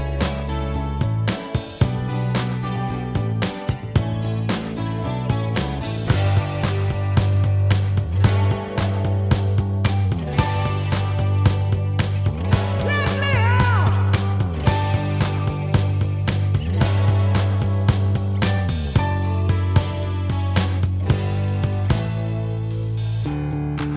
One Semitone Down Pop